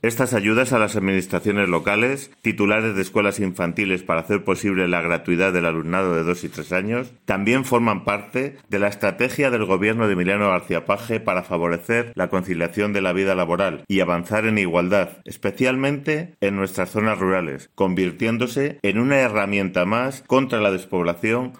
El delegado provincial de Educación, Ángel Fernández-Montes, ha subrayado que “estas ayudas a las administraciones locales, titulares de escuelas infantiles, para hacer posible la gratuidad del alumnado de 2 a 3 años también forman parte de la estrategia del Gobierno de Emiliano García-Page para favorecer la conciliación de la vida laboral y avanzar en igualdad, especialmente en nuestras zonas rurales, convirtiéndose en una herramienta más contra la despoblación”.